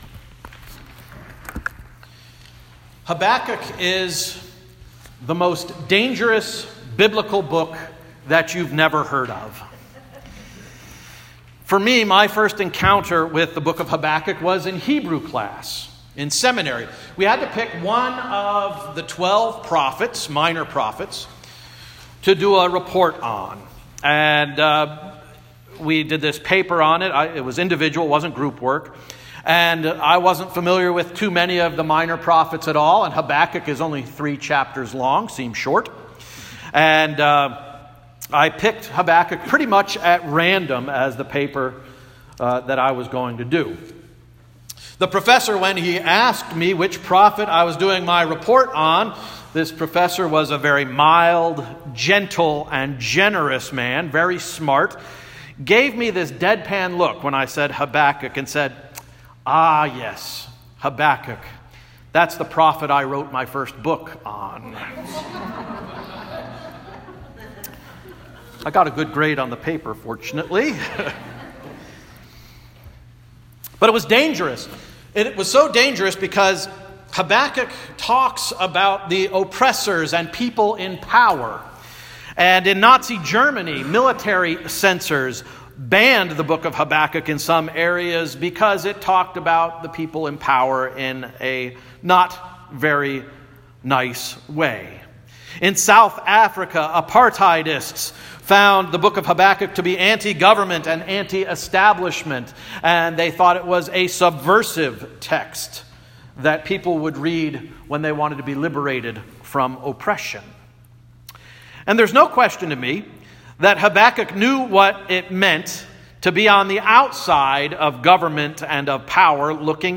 Sermon of December 2, 2018 — “Faith as a Way of Life”